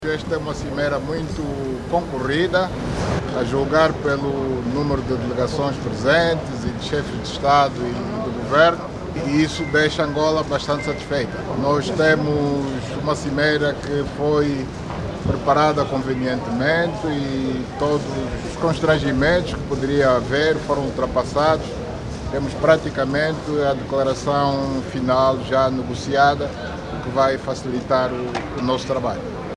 A declaração foi feita no Salão Protocolar, local que acolhe a Sétima Cimeira União Africana -União Europeia.